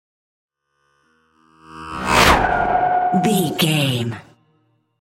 Sci fi vehicle whoosh
Sound Effects
dark
futuristic
intense
whoosh